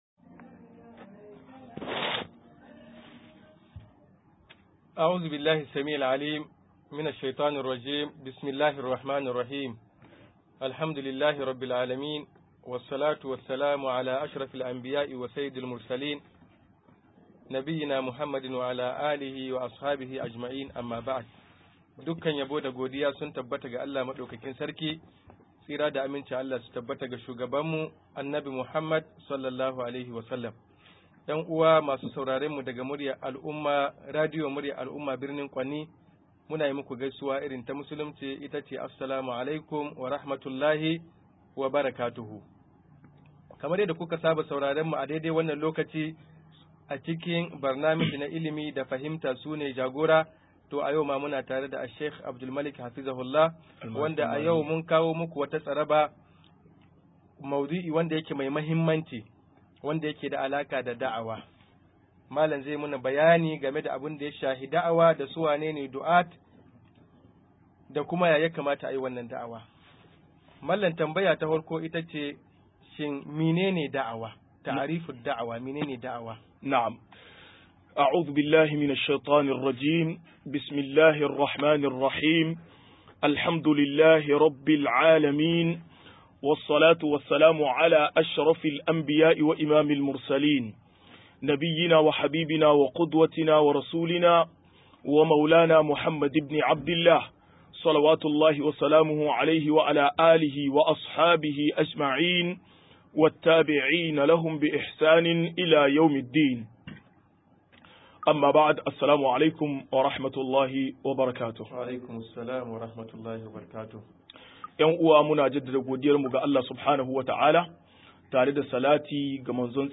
203-Yadda ake Da a wa - MUHADARA